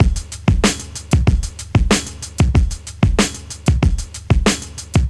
• 94 Bpm Drum Loop Sample C Key.wav
Free drum beat - kick tuned to the C note. Loudest frequency: 1226Hz
94-bpm-drum-loop-sample-c-key-IXK.wav